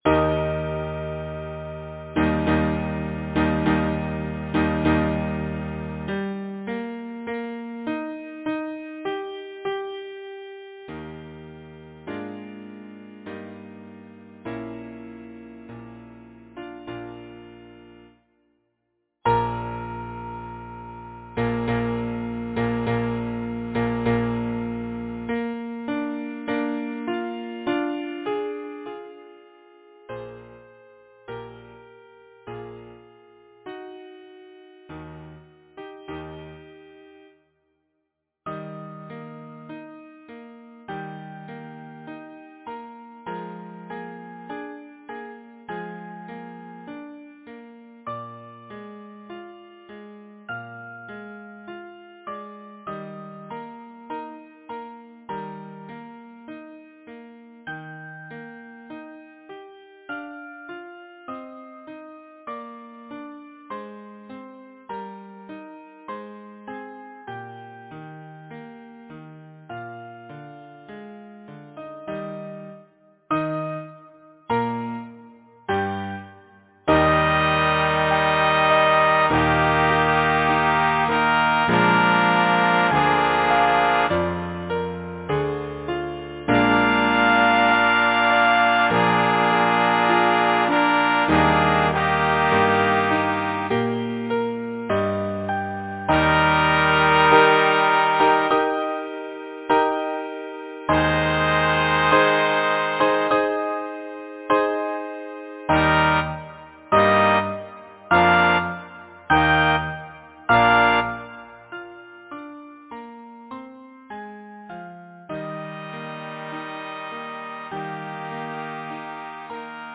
Title: Daughter of error! Composer: Henry Rowley Bishop Lyricist: Number of voices: 4vv Voicing: SATB Genre: Secular, Partsong, Glee
Language: English Instruments: Piano